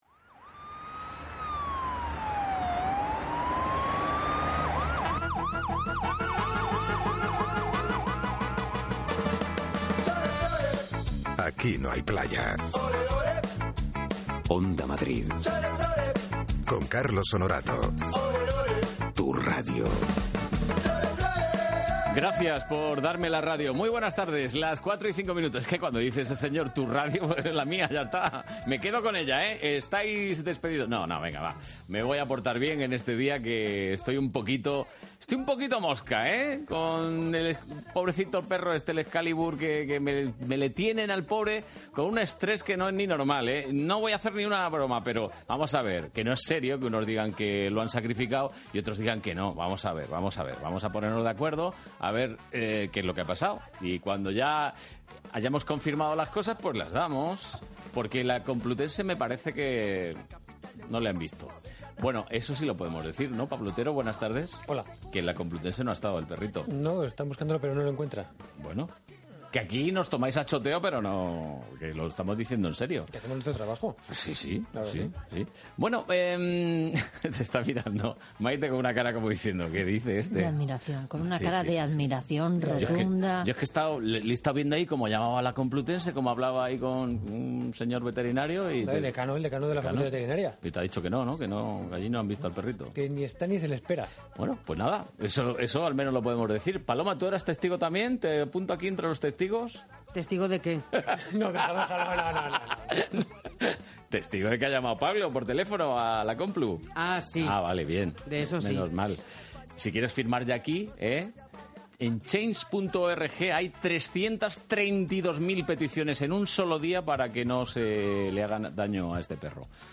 Radio: Podcast Divertida entrevista en «Aqui no hay playa». 8 Octubre 2014